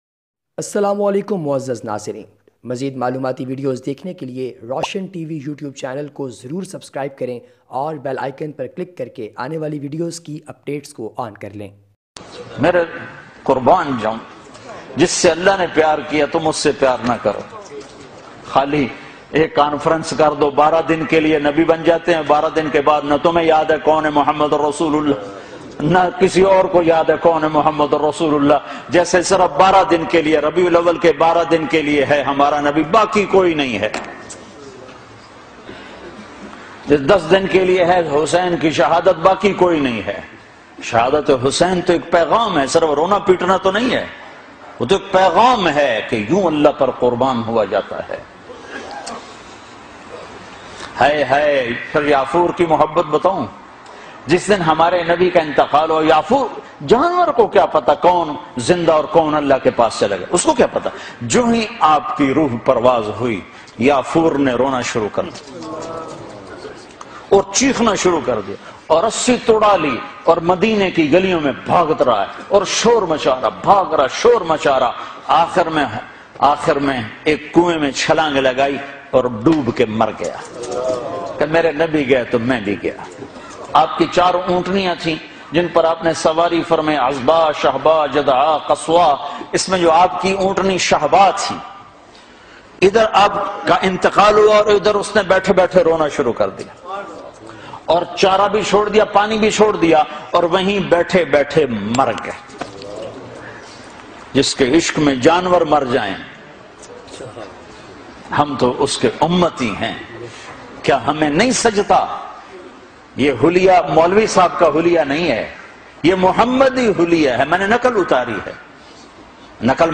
12-Rabi-ul Awal-Bayan.mp3